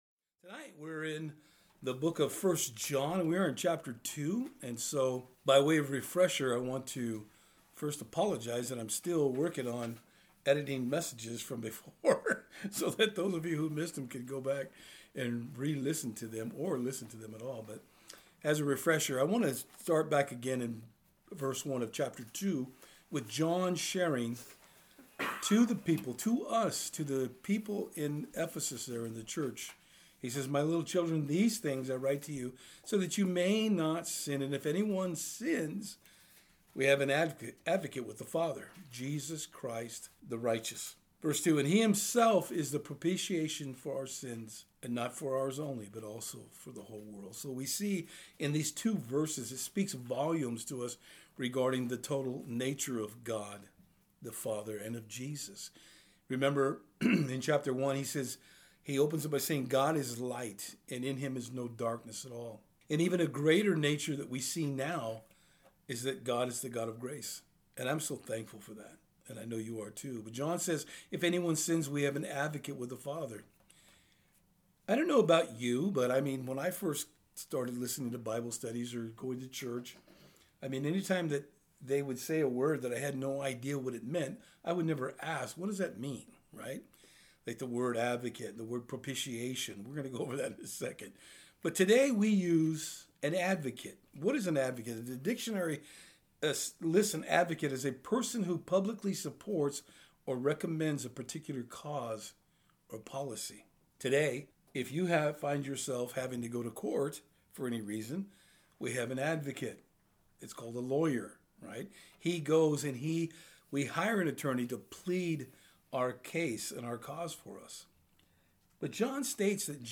1 John 2:3-10 Service Type: Thursday Eveing Studies The Apostle John clarifies to us what it means to truly be a follower of Jesus Christ.